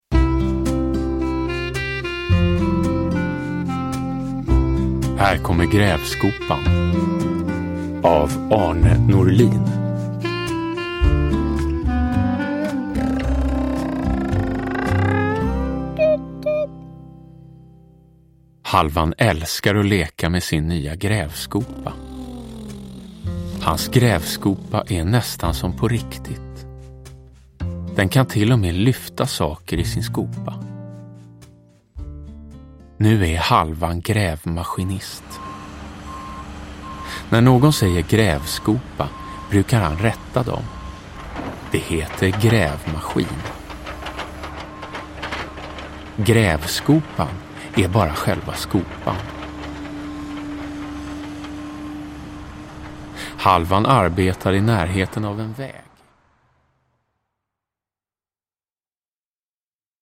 Uppläsare: Jonas Karlsson